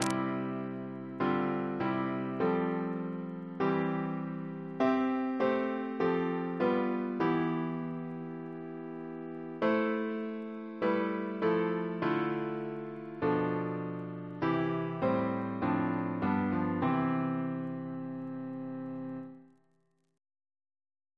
Double chant in C Composer: John Joubert (1927-2019) Reference psalters: ACP: 8